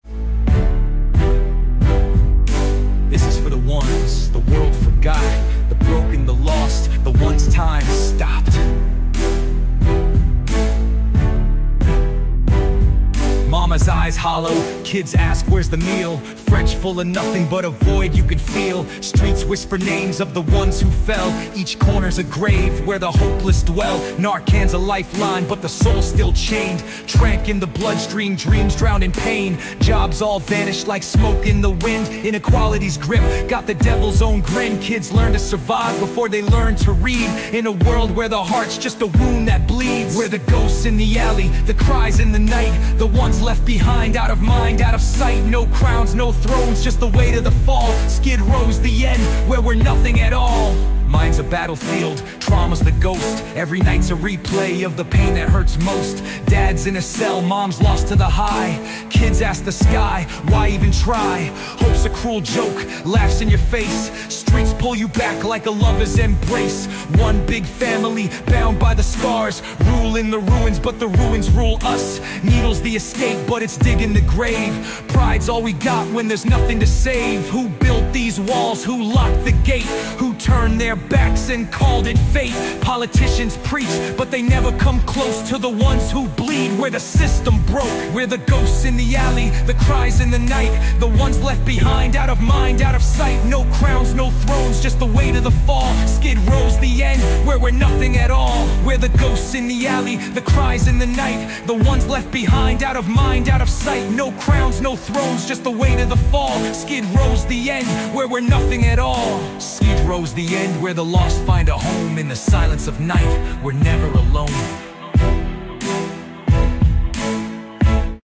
Skid Row Kings Ghetto Gods Reborn 2025 Rap Anthem